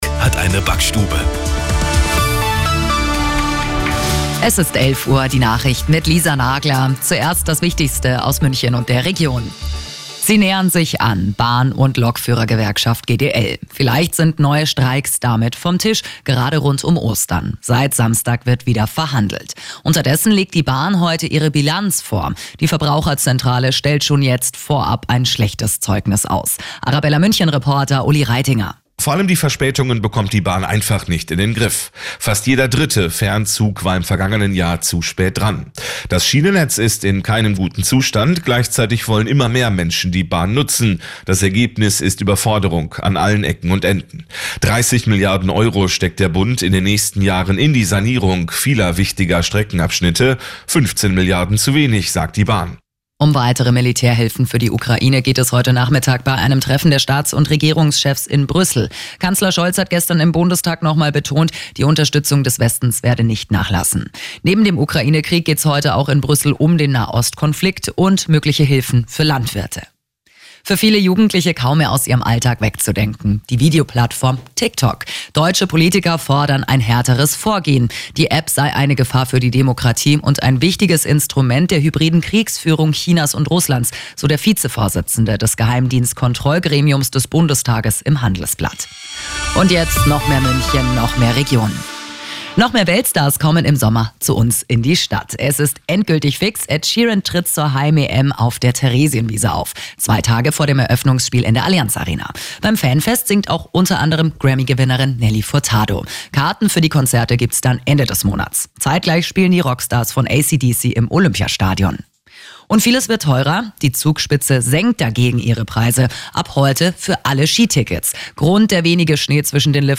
Die Arabella Nachrichten vom Donnerstag, 21.03.2024 um 16:06 Uhr - 21.03.2024